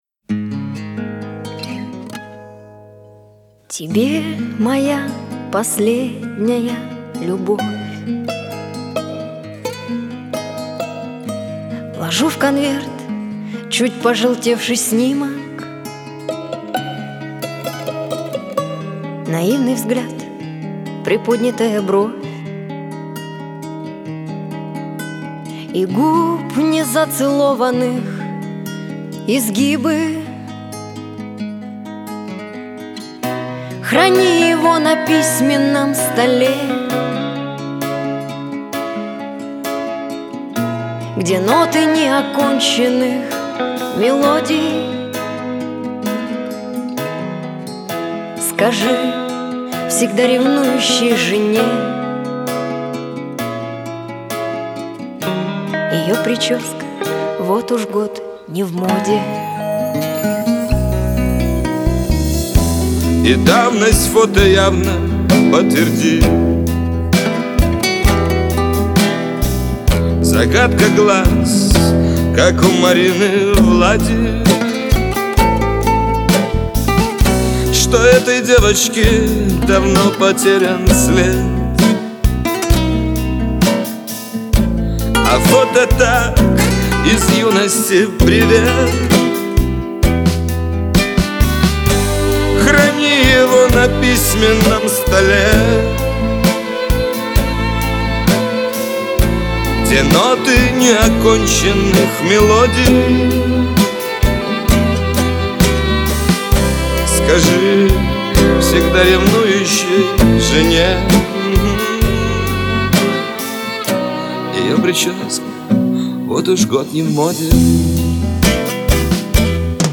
Но одна песня мне очень понравилась, наверное потому что больше походит на романс чем на шансон.